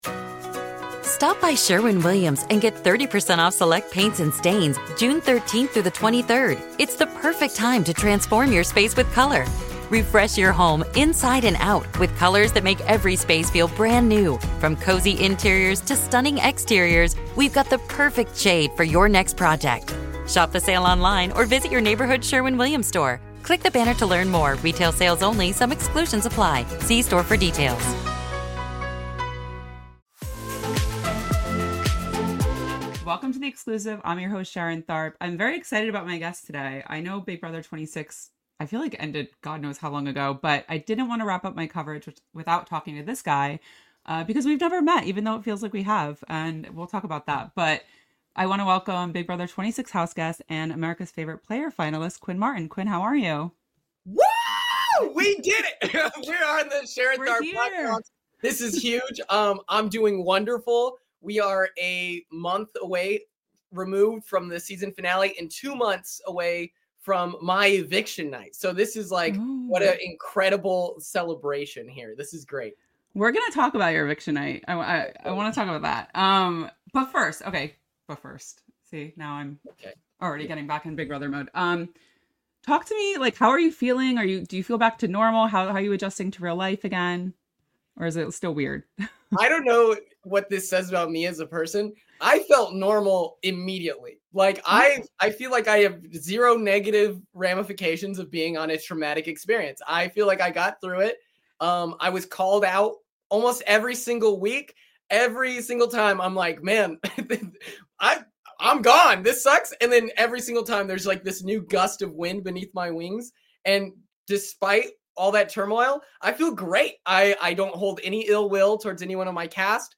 Big Brother 26 Post-Season Interview